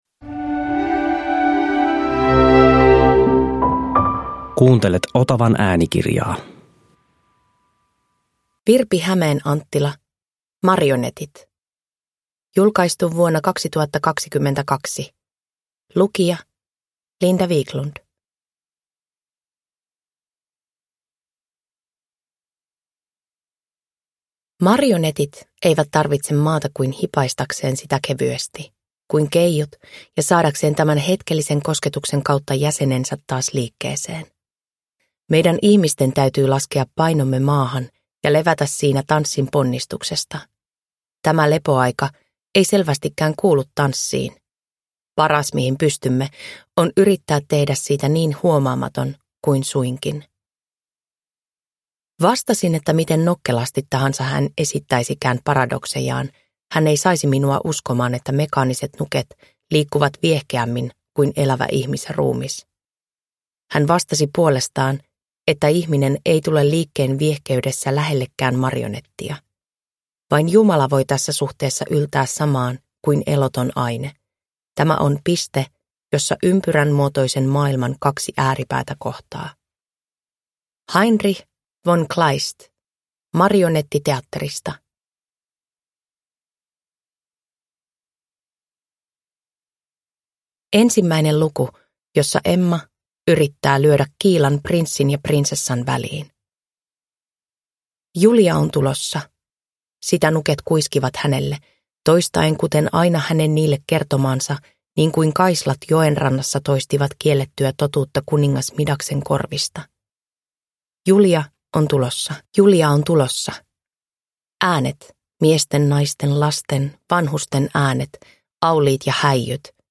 Marionetit – Ljudbok – Laddas ner